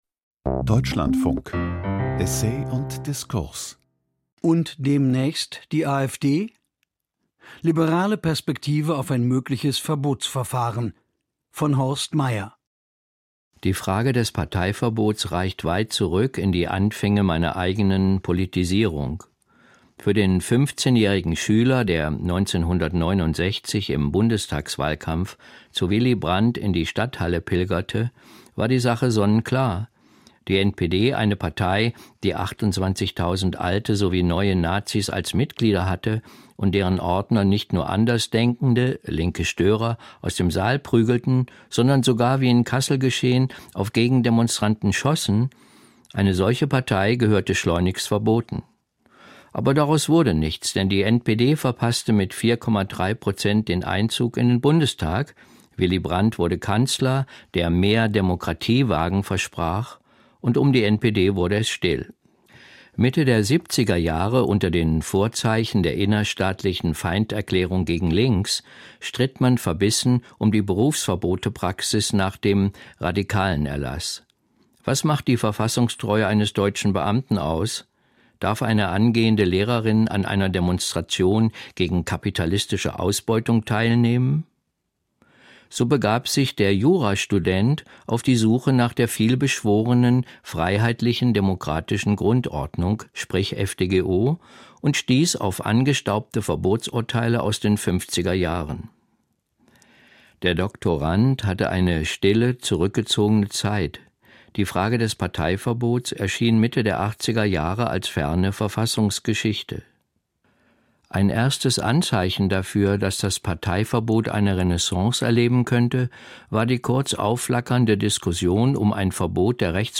Essay Essay und Diskurs, Deutschlandfunk, 30.